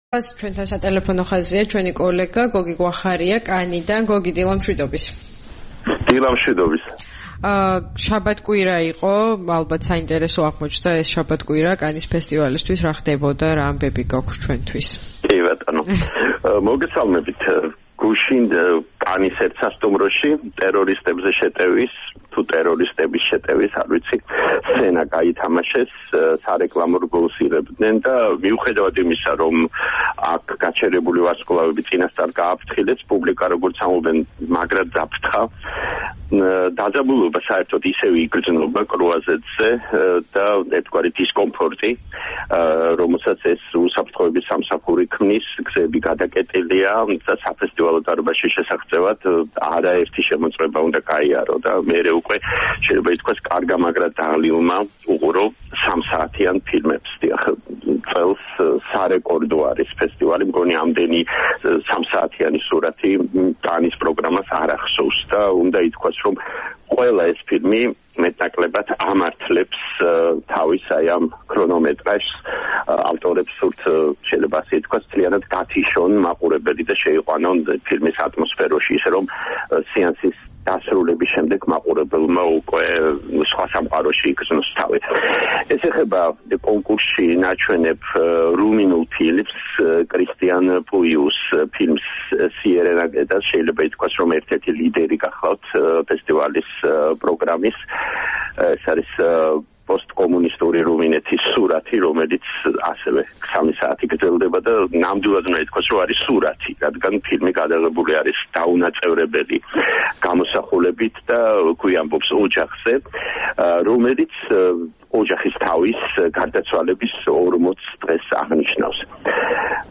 კანის საერთაშორისო კინოფესტივალიდან